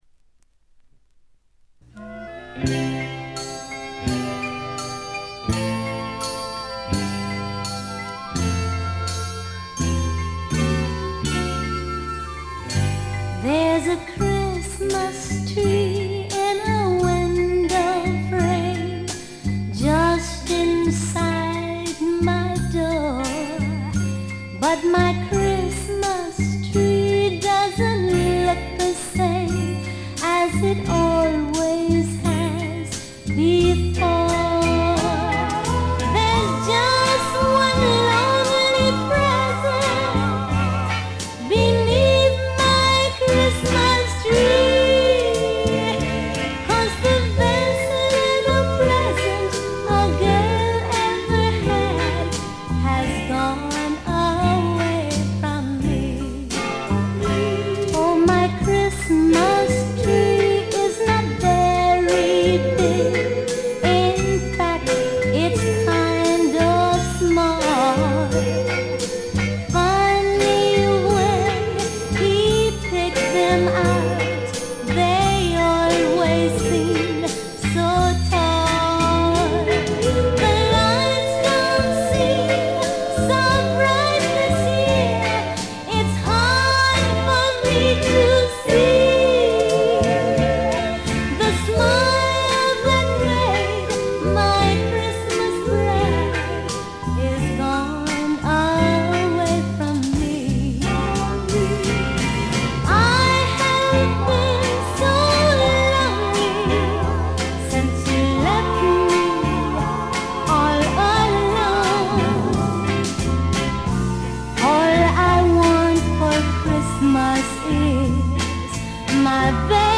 ガールポップ基本。
試聴曲は現品からの取り込み音源です。